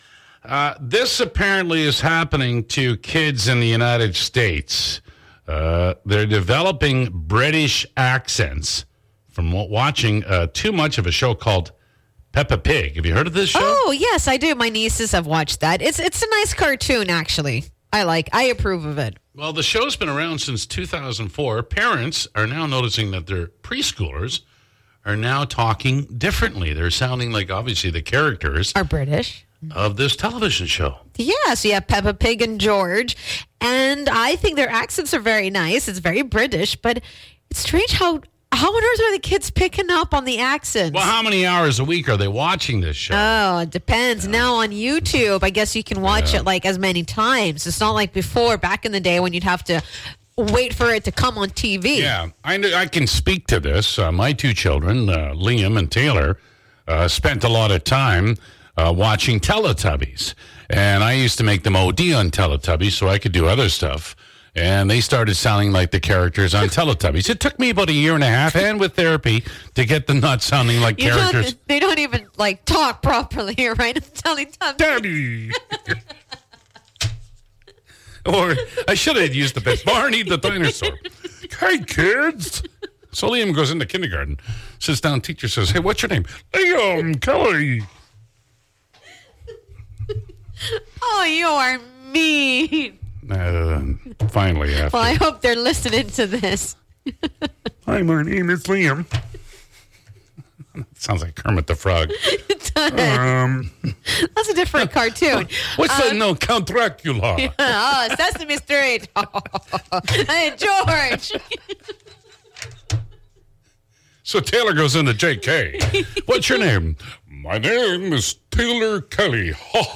The Morning Crew were shooting the breeze today, and one of the topics that came up in today’s conversation was children’s television shows.